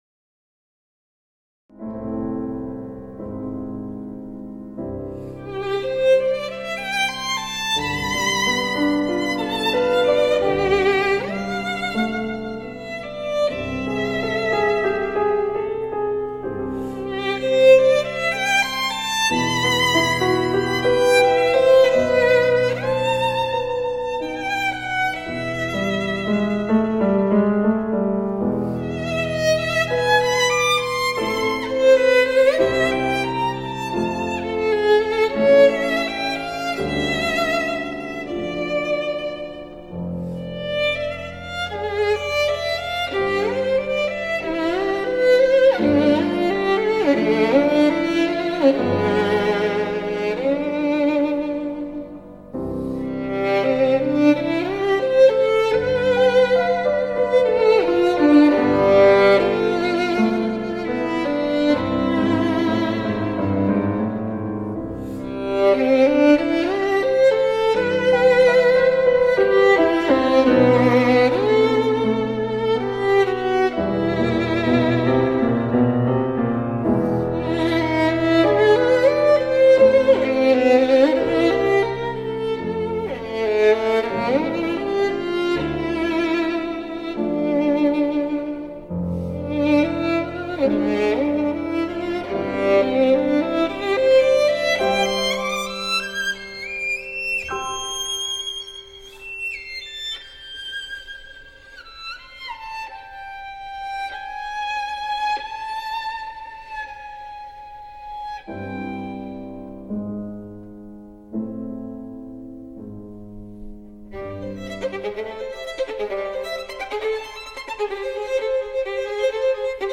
引子思绪绵长，似忧愁徜徉，怀念与渴望的蔓延；应当配上钢琴伴奏、和身着轻纱缓缓旋转的舞女。
忽的进入舞曲部分，干净利落、绚丽激昂，速度极快，6/8拍连续不断的三连音，情绪热烈。
充满想象力又深情美好。